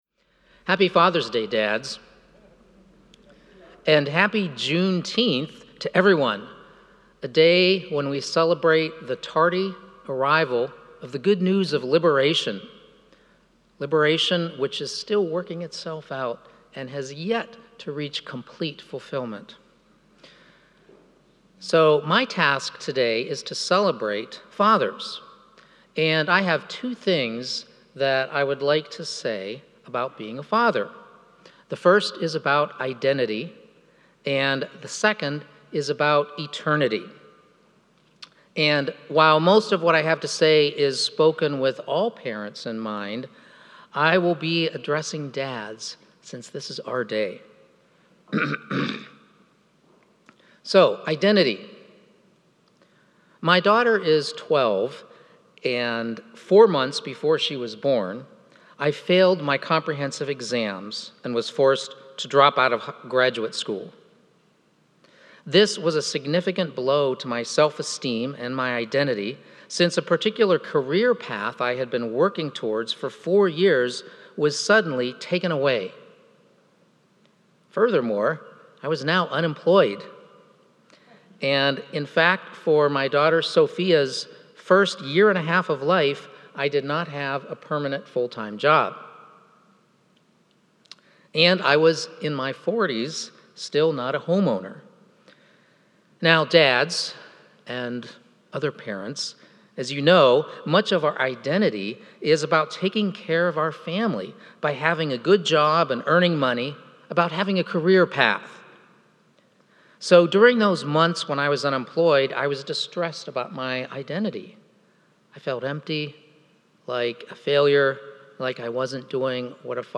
Father's Day Liturgy 2022